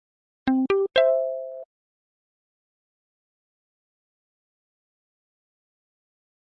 电话 " 无信用信息
描述：您在英国的Orange移动网络上收到的错误消息。它只是告诉我手机上没有信用卡，并告诉我拨打电话号码。录制了第五代iPod touch。用Audacity编辑。
Tag: 语音 消息 橙色 错误 女性 打电话 手机 电话 EE 手机 信用卡 女人 公告 没有信用